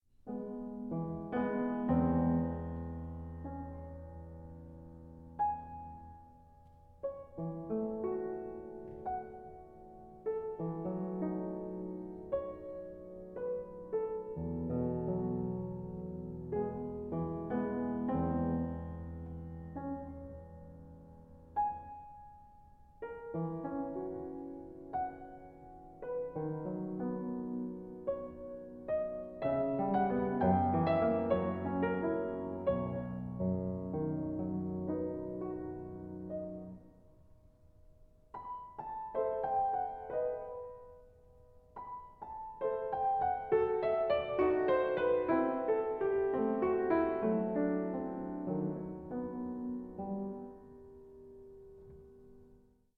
Stereo
CD1 - 1868 Erard Grand Piano, Paris, France